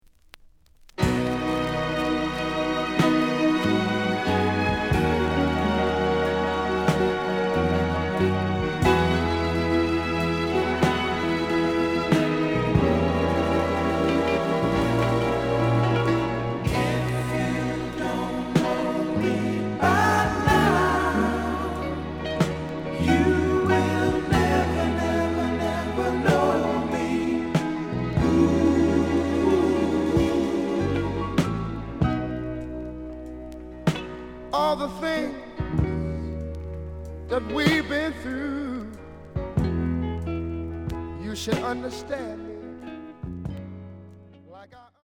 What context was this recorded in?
The audio sample is recorded from the actual item. Slight noise on beginning of A side.